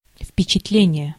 Ääntäminen
Ääntäminen US : IPA : [ɪmˈprɛ.ʃən] Tuntematon aksentti: IPA : /ɪmˈpɹɛʃən/ Lyhenteet ja supistumat imp.